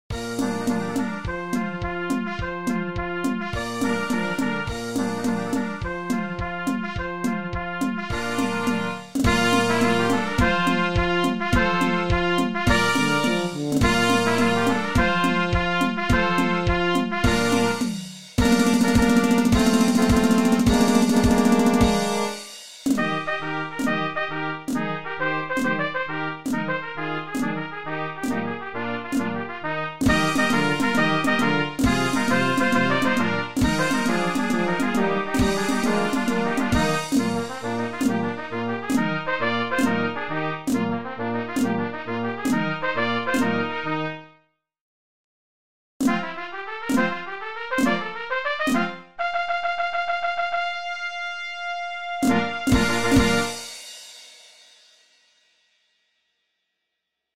pièce avec Soliste